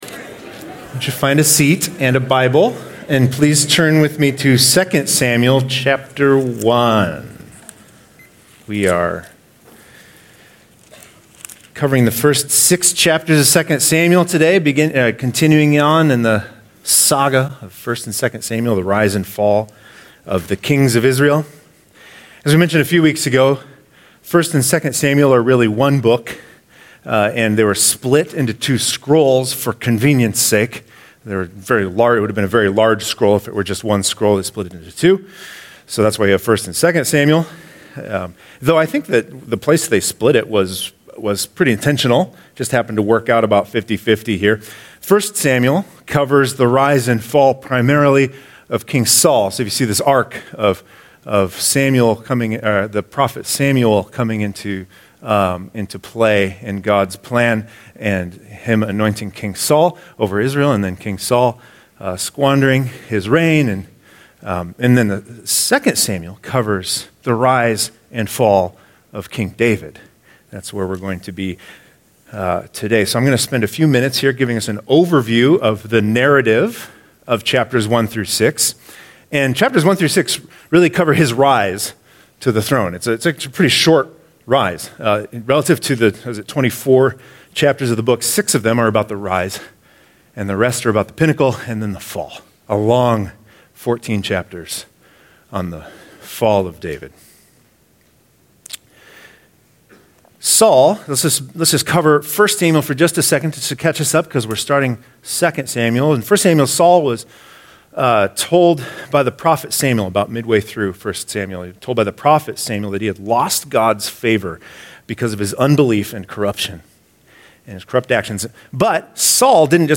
Download sermon notes and manuscript: (PDF): Download (DOCX) Download Sermon manuscript: Please turn with me to 2 Samuel chapter 1.